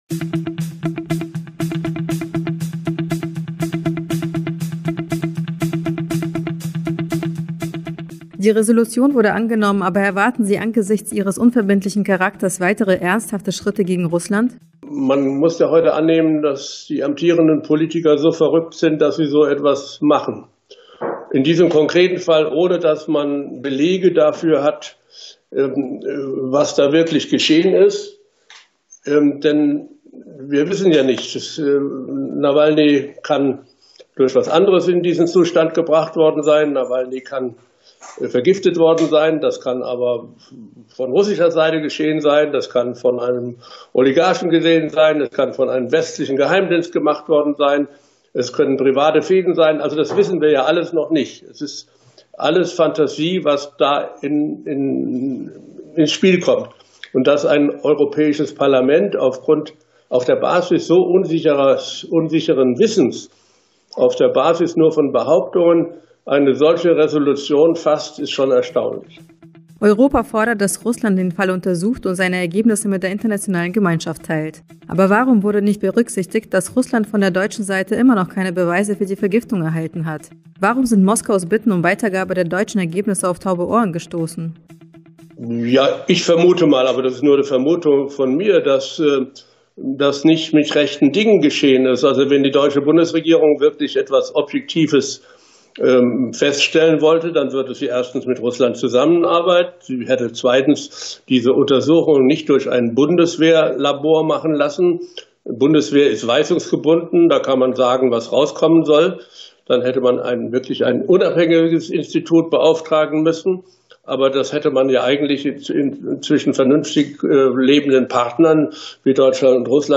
Ein Interview zum Thema